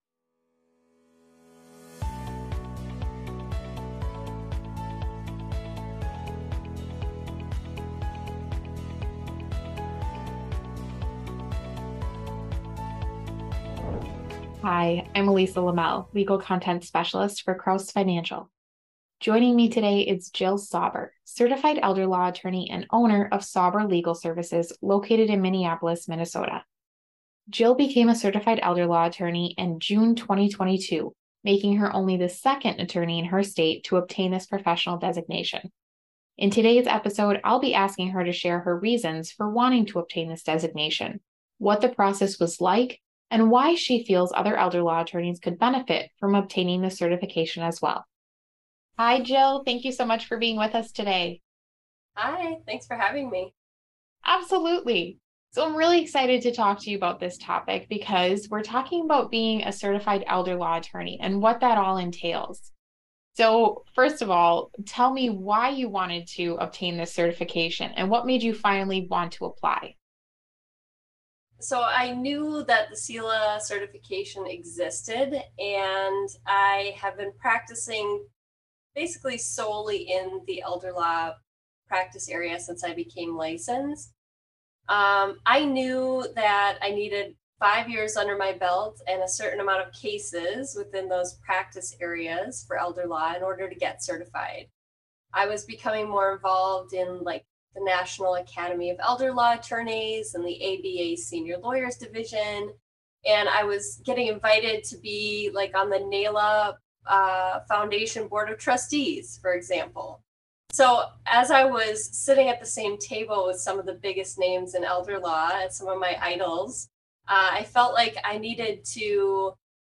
Elder Law Interview